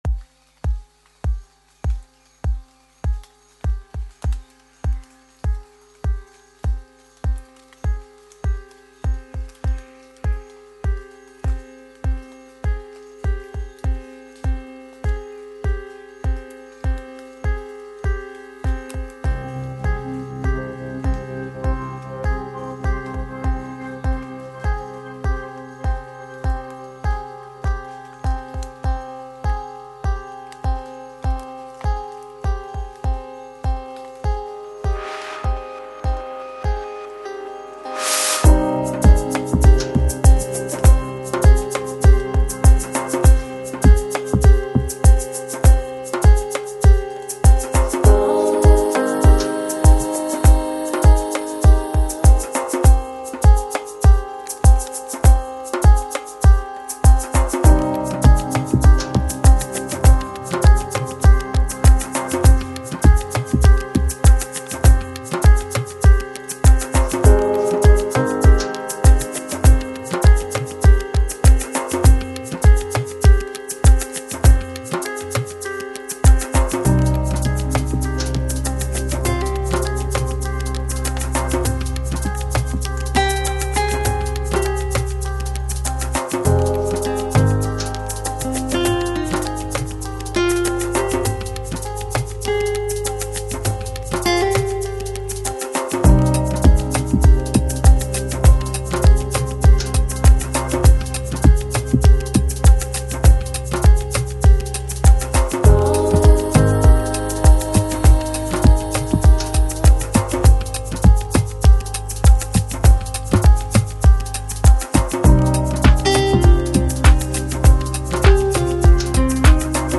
Chill Out, Downtempo, Organic House Продолжительность